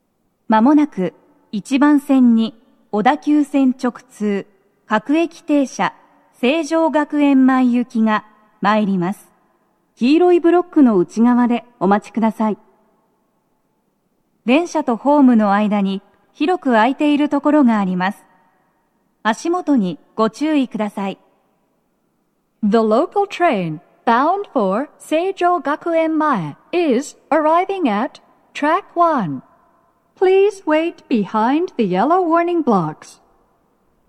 スピーカー種類 BOSE天井
鳴動は、やや遅めです。鳴動中に入線してくる場合もあります。
接近放送2